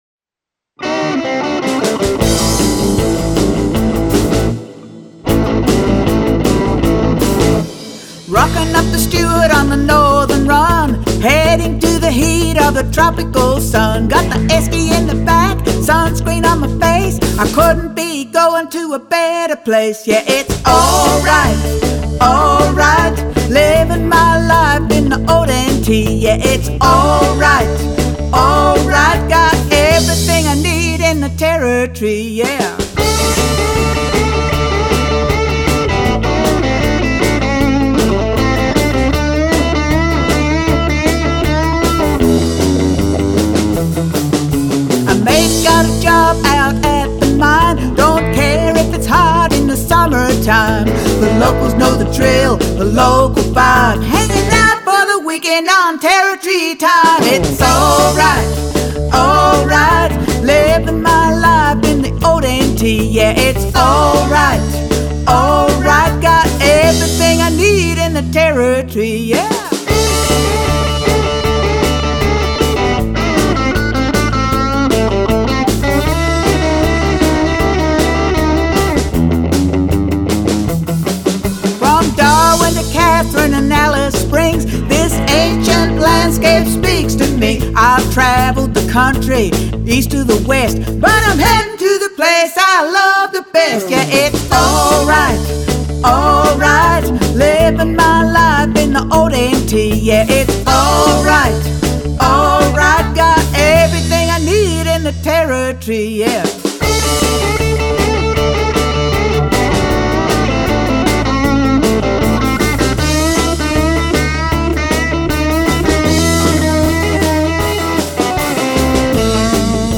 lead vocals
dynamic Telecaster lead guitar solos
harmonies, bass guitar and drums